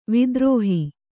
♪ vidrōhi